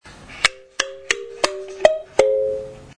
木箱竹爪カリンバ【アフリカ 民族楽器】 (w135-14)
鉄爪と違い小さな音ですが竹と木の組み合わせは柔らかくて深みのある、とても魅力的な音が出ます♪
この楽器のサンプル音